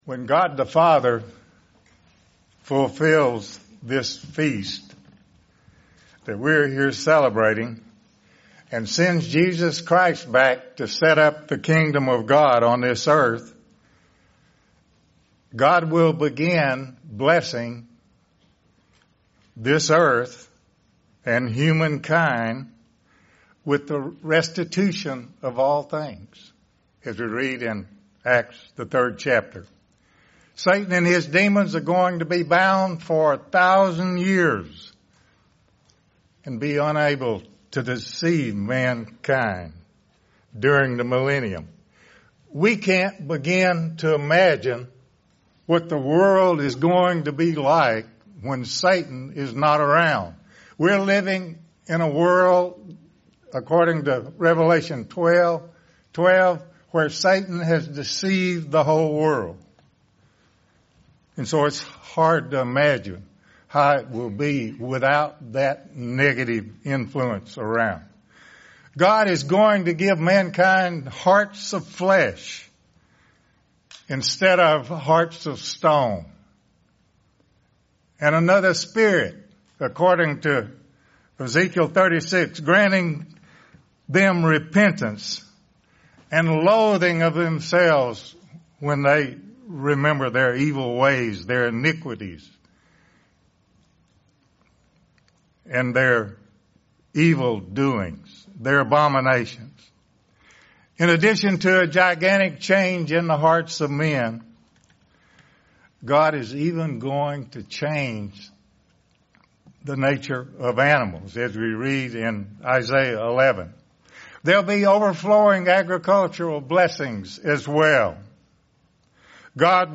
This sermon was given at the Branson, Missouri 2019 Feast site.